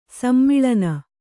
♪ sammiḷana